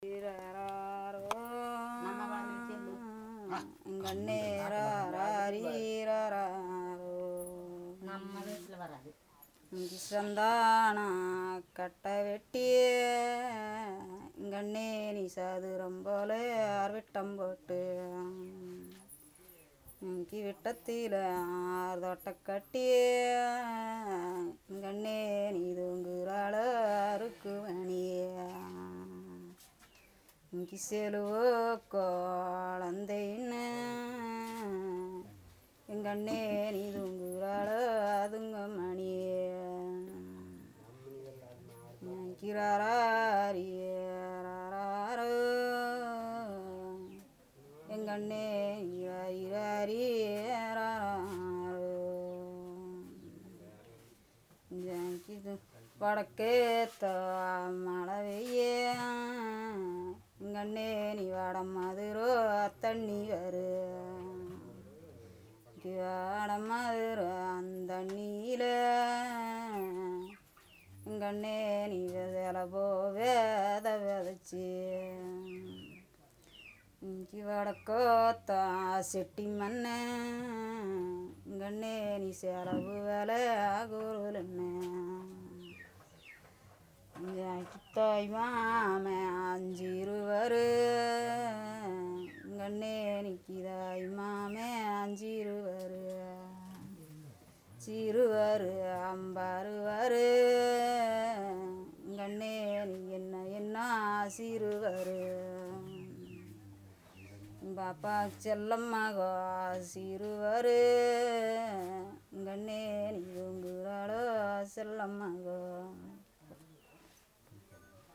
Performance of a lullaby